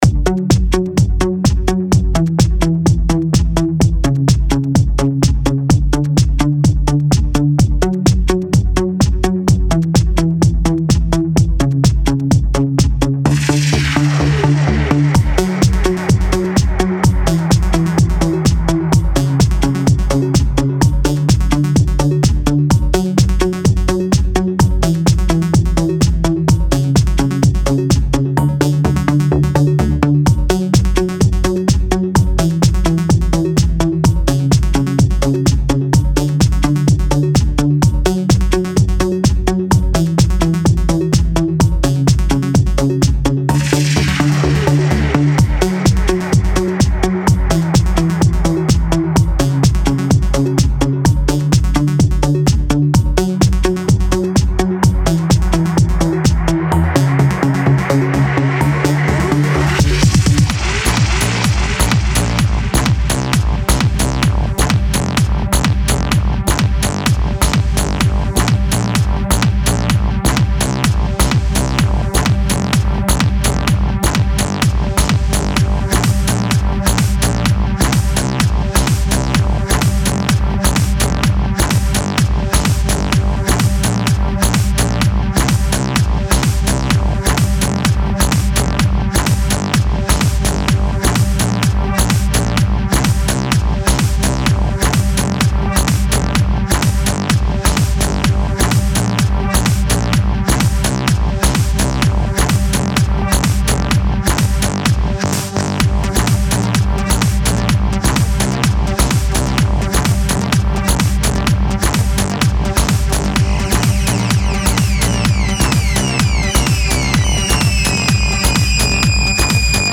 All loops are royalty-free and recorded using analog gear
at 16bit 44.1 kHz 120 BPM, recommended by Sony Acid.
Beats, Drum Loops and Percussions:
270 minimal, deep, analog, glitchy, techy and funky beats
Analog Synthlines, Chordlines and Pads;
Analog Basslines:
Analog Leadlines, Arps and Sequences;